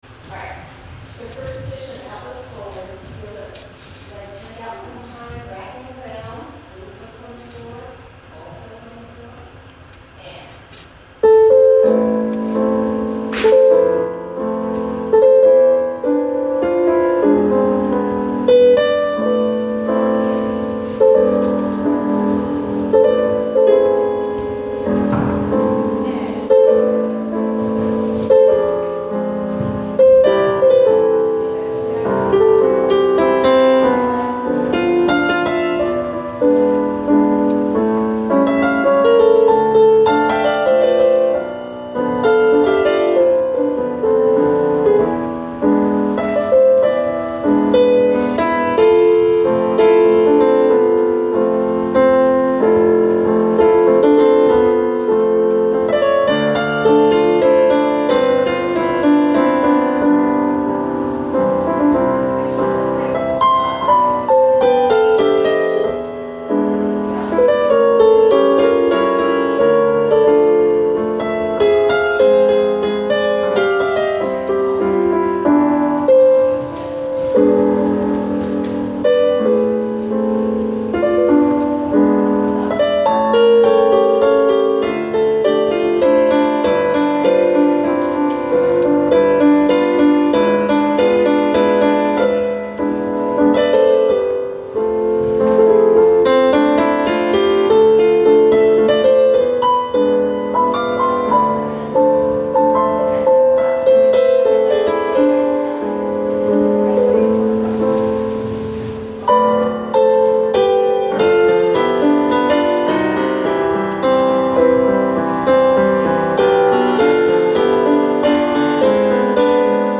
First 2/3 of impromptu improv I played for pliés in late March. 4-minute file wouldn't load so I split it in two parts.
I wanna call this 3/4 time improv -- which shows I've listened to not a little Chopin in my time plus a bit of Satie now and then -- either: .